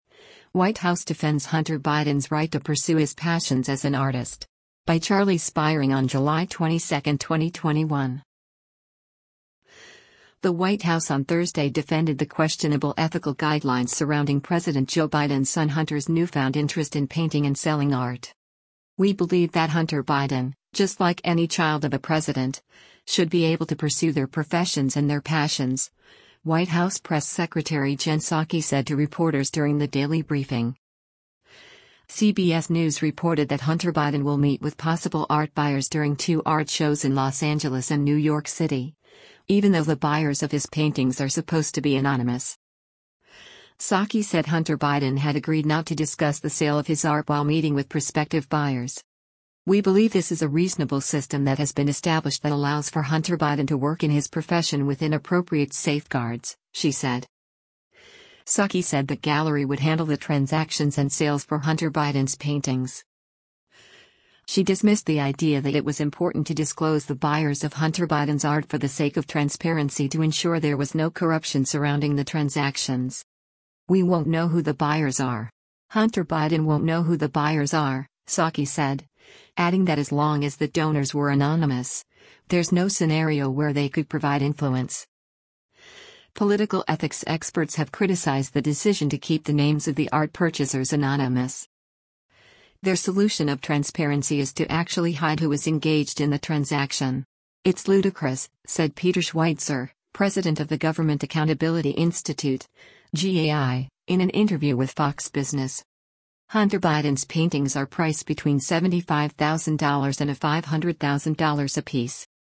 “We believe that Hunter Biden, just like any child of a president, should be able to pursue their professions and their passions,” White House press secretary Jen Psaki said to reporters during the daily briefing.